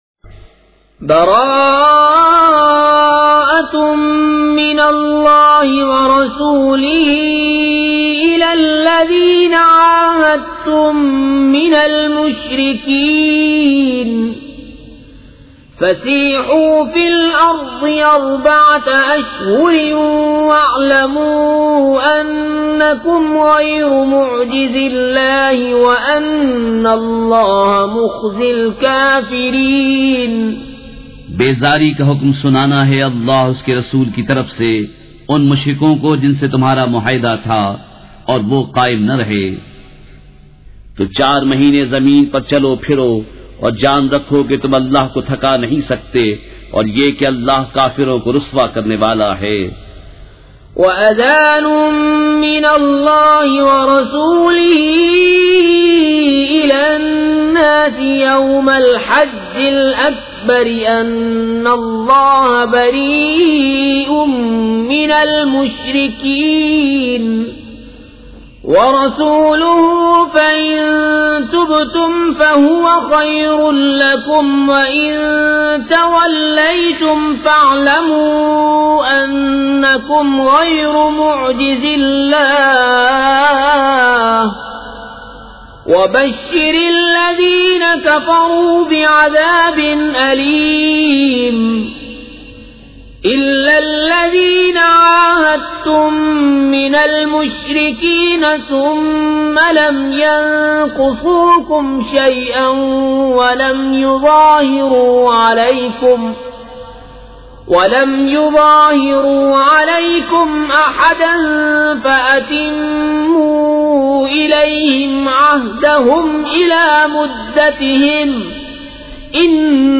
سورۃ التوبہ مع ترجمہ کنزالایمان ZiaeTaiba Audio میڈیا کی معلومات نام سورۃ التوبہ مع ترجمہ کنزالایمان موضوع تلاوت آواز دیگر زبان عربی کل نتائج 2997 قسم آڈیو ڈاؤن لوڈ MP 3 ڈاؤن لوڈ MP 4 متعلقہ تجویزوآراء